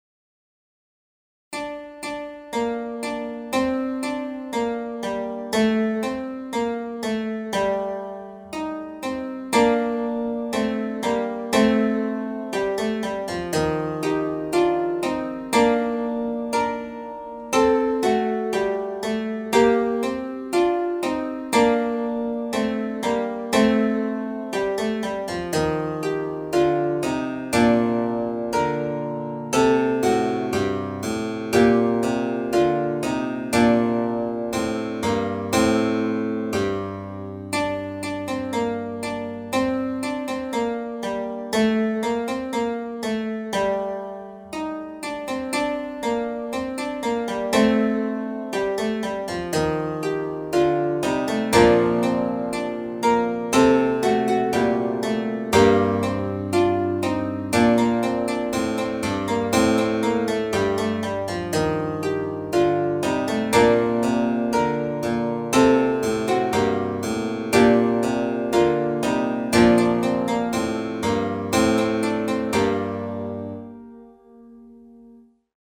adding diminuitions in a- and g-, idiomatic to each key-